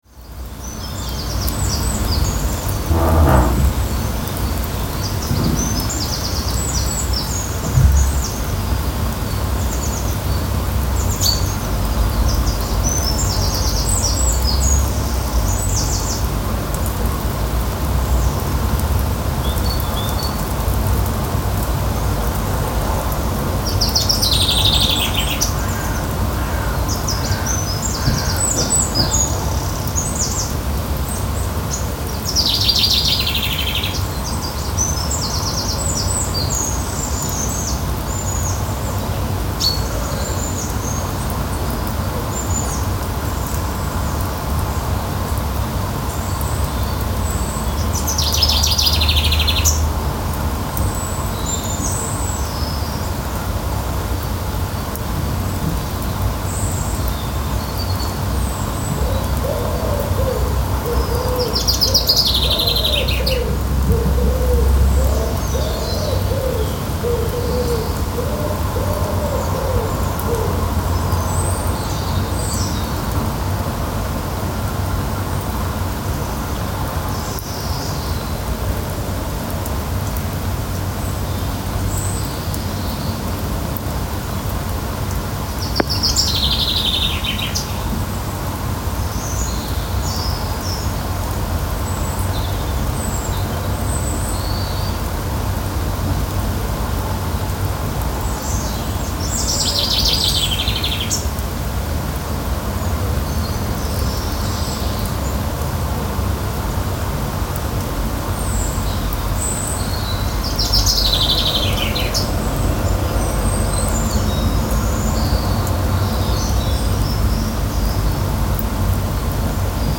We can hear the "noises" coming from the garden of the house. This house is located at the entrance to the city, right behind a commercial area.
The sun emerging from the gray morning mass, birds, light and other small elements are taking possession of the place. The walls cut off the sounds coming from the commercial area, the garden extends to the edge of the river below. In the distance, we can hear the sounds of the city.